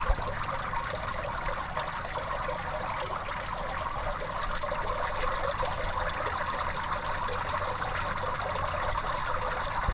We ended up recording our sounds using the zoom voice recorder.
Made world (fountain):